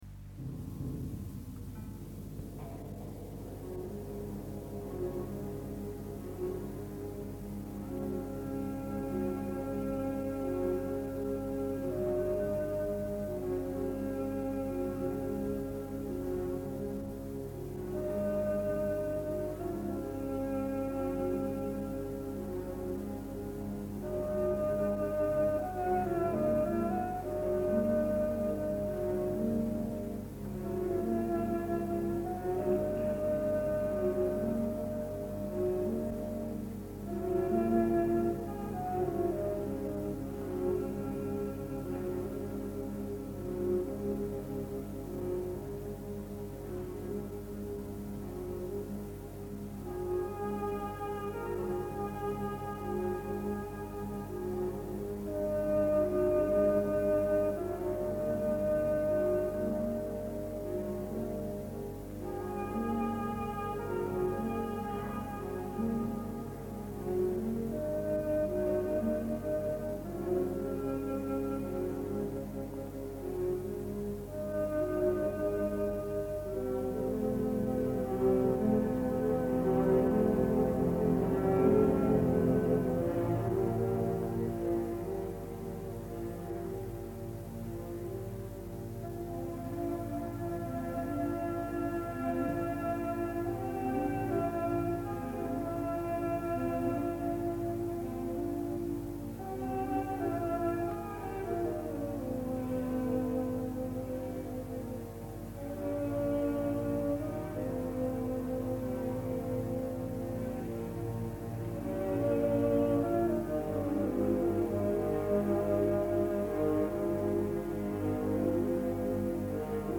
poema sinfonico
Auditorium Rai di Torino, 30 aprile 1953)